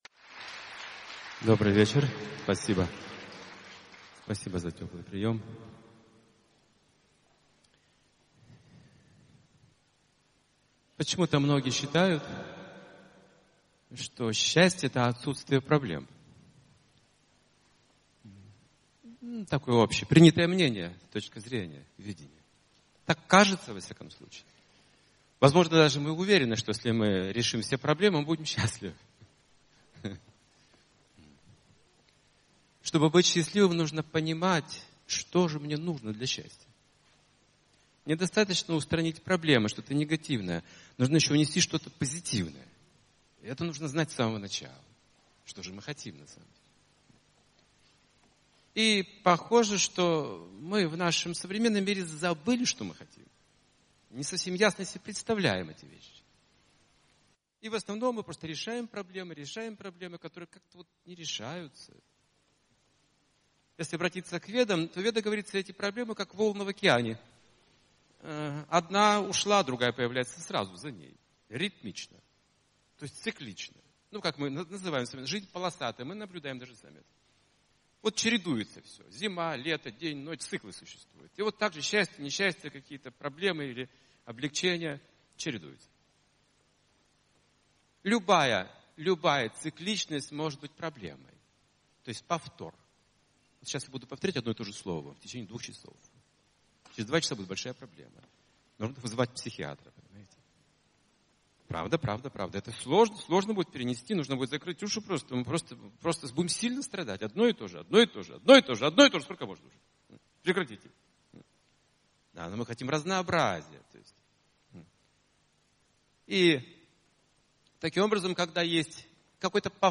Недостаточно устранить негативное, нужно еще привнести позитивное. Лекция о том, как понять смысл страданий, их связь со счастьем, и достичь истинного удовлетворения и гармонии.